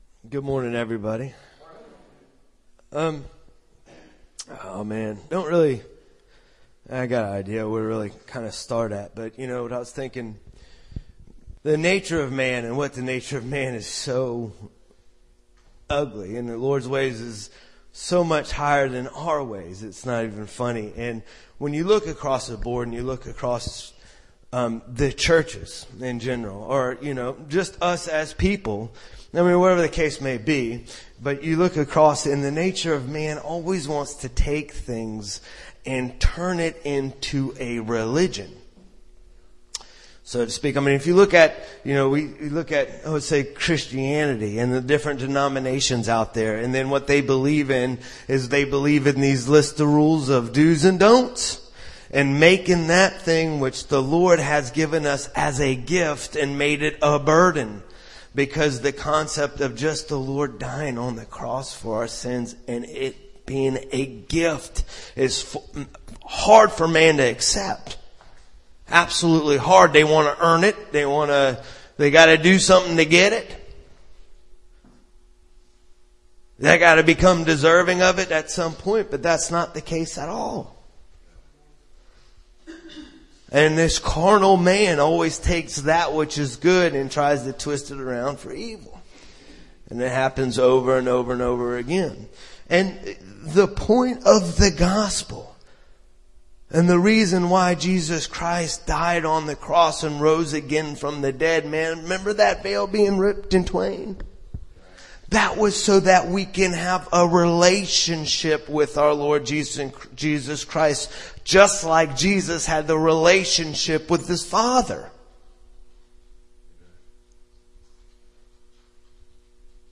Sunday South Carolina Church Service 04/05/2015 | The Fishermen Ministry